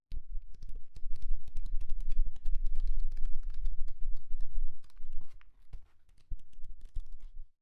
Das schnelle durchblättern eines Buches bewirkt nicht nur Wind sondern auch ein Geräusch, dass nicht so leicht zu erkennen ist.
umblaettern